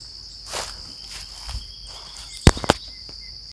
Rufous Gnateater (Conopophaga lineata)
Location or protected area: Parque Provincial Teyú Cuaré
Condition: Wild
Certainty: Photographed, Recorded vocal